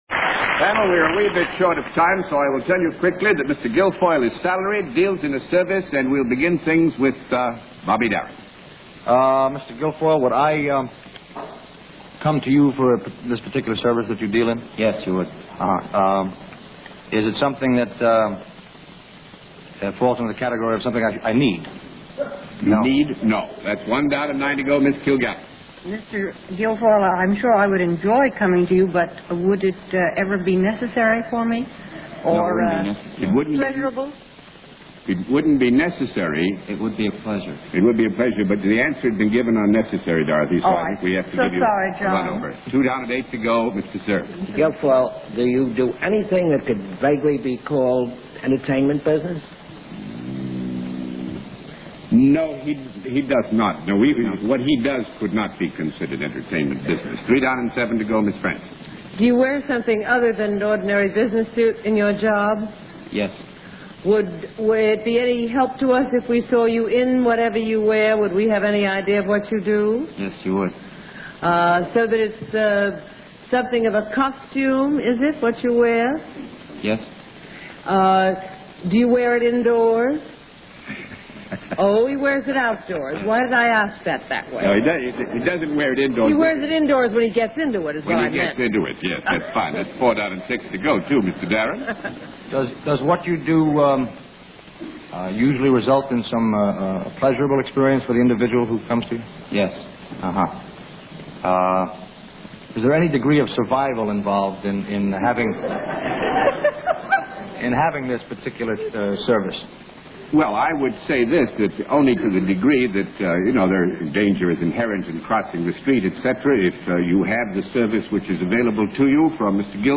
Mr. Darin and the panel quizzing another contestant, Bobby gets some good natured ribbing
from host John Charles Daly about the way he talks, and Bobby uses it to get a good laugh!